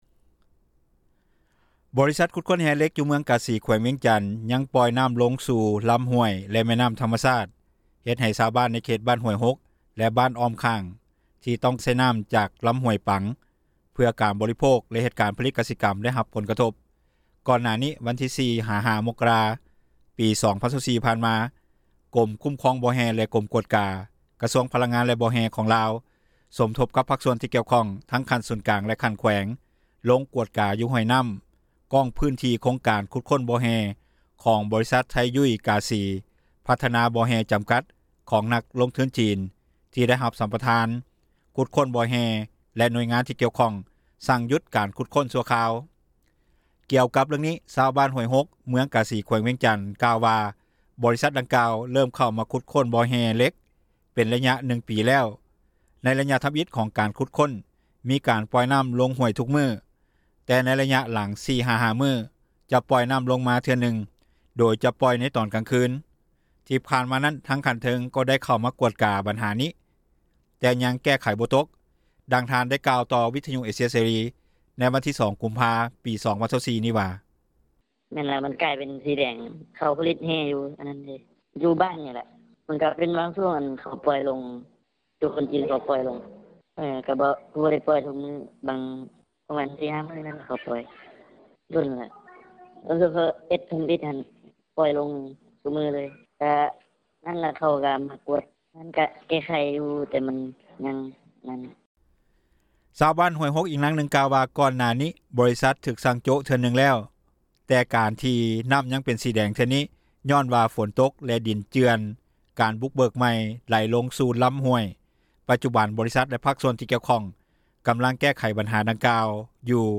ດັ່ງຍານາງກ່າວວ່າ:
ດັ່ງເຈົ້າໜ້າທີ່ ທີ່ກ່ຽວຂ້ອງ ຂັ້ນທ້ອງຖິ່ນກ່າວວ່າ: